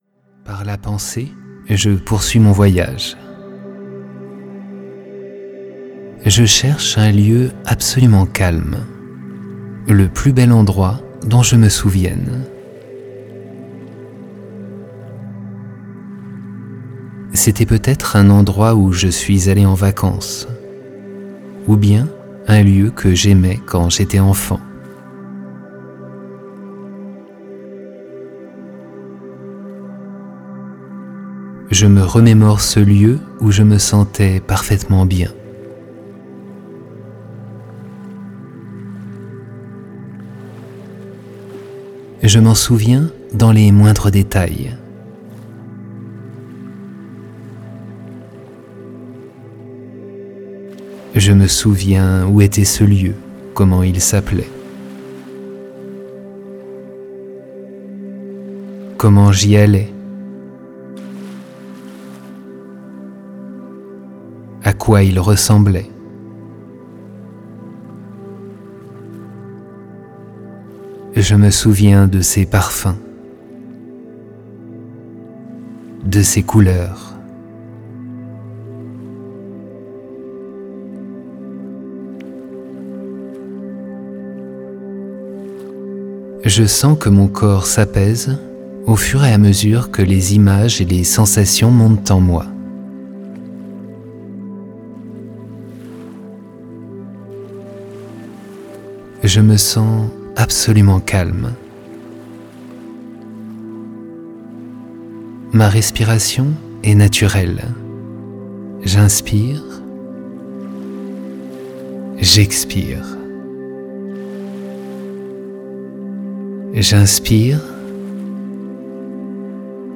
Méditations guidées pour débutants : une collection de voyages intérieurs pour faire le plein d'énergie positive